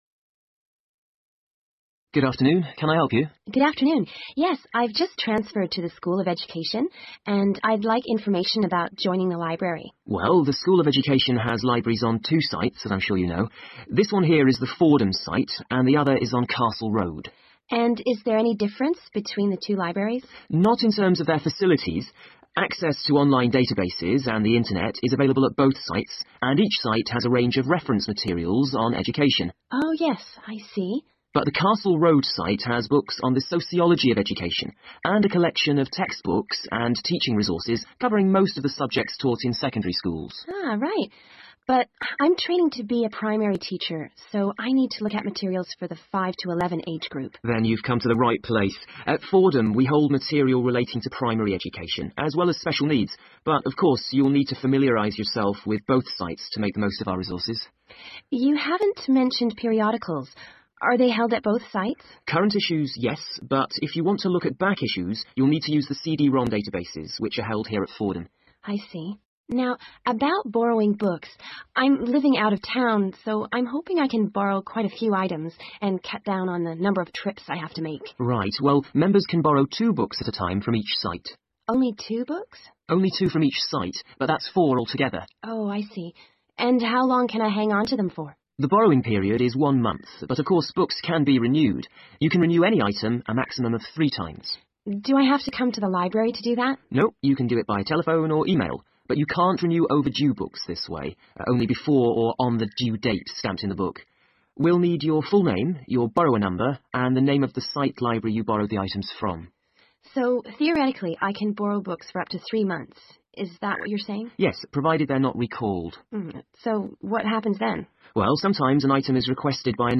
Chủ đề “The School of Education Libraries” là một ví dụ điển hình, nơi sinh viên trao đổi thông tin liên quan đến thư viện, tài liệu học tập hoặc dịch vụ hỗ trợ trong môi trường đại học.